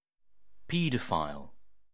Uttal
Alternativa stavningar pedophile Synonymer pedophile pædophile Uttal UK Okänd accent: IPA : /ˈpi.dəˌfaɪl/ Ordet hittades på dessa språk: engelska Ingen översättning hittades i den valda målspråket.